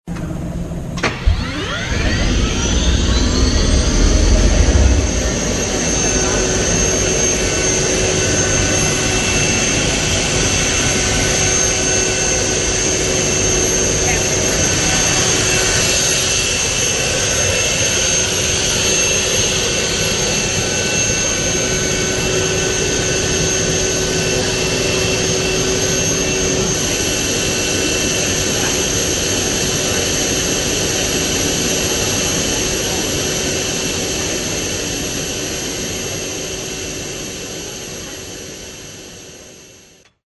It had a sound like a jet taking off,  and I would hear that sound many times from the music room, and every time I had to get up to see it again.
LISTEN TO THE CHRYSLER TURBINE START UP
Turbine.mp3